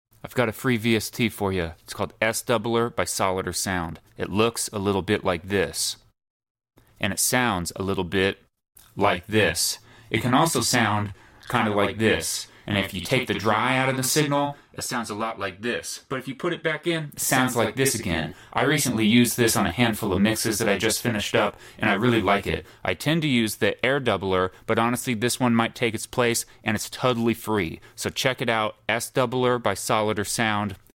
Very nice free vst from solidersound called s doubler. If you’ve ever wanted to hear a bunch of yourself here you go!!